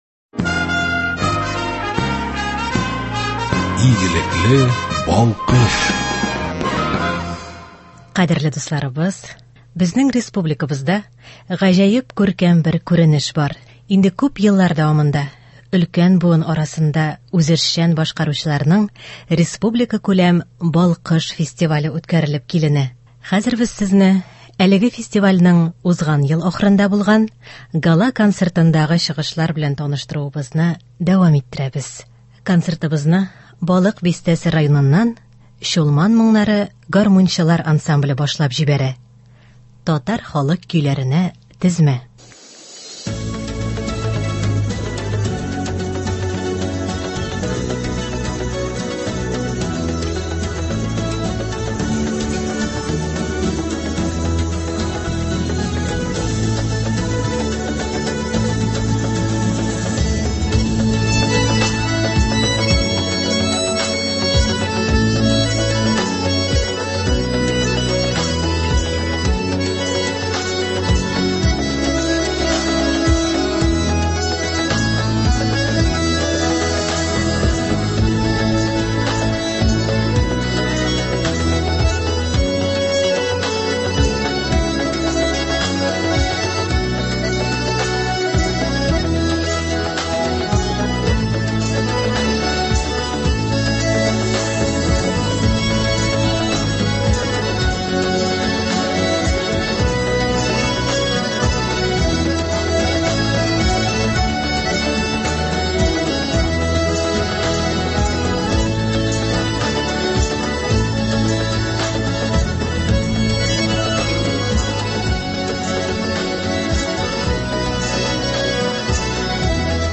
“Балкыш” республика фестиваленең Гала-концерты.